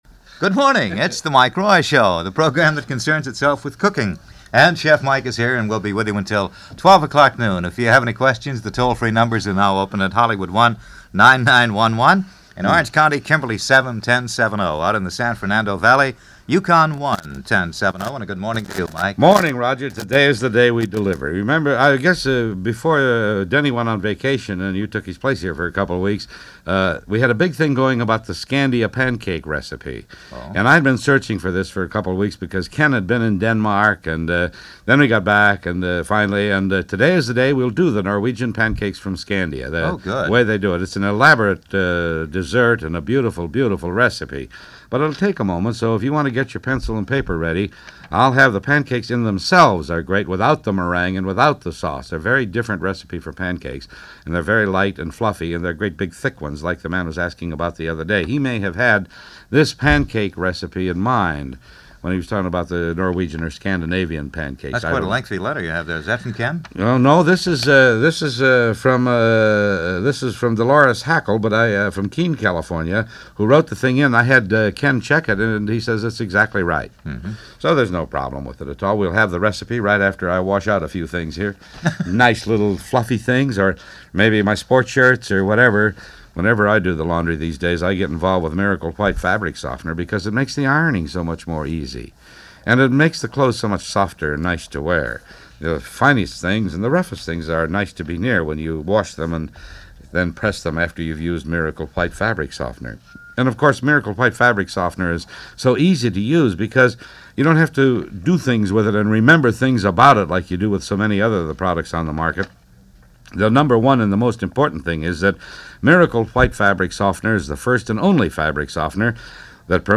His show was a two-way communication; taking phone calls from people who were just getting acquainted with the average kitchen to those who made cooking and all things gastronomic something of a calling in life.